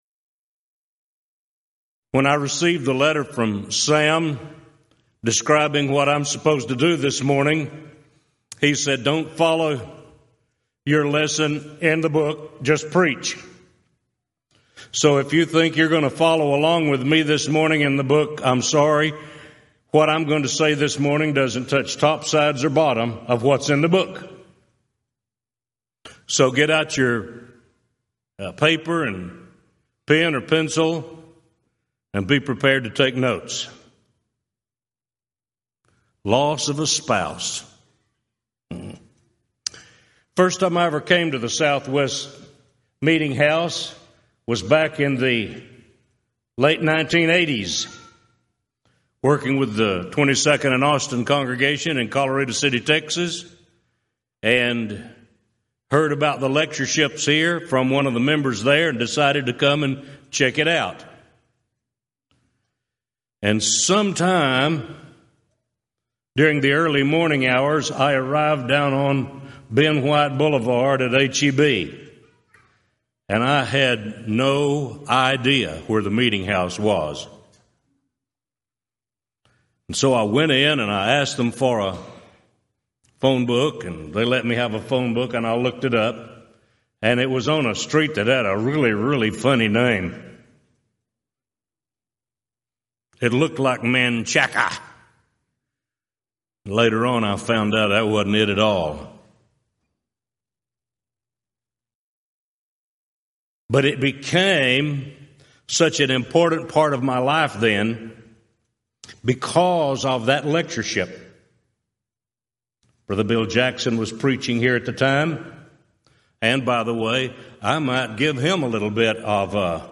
Event: 34th Annual Southwest Lectures
lecture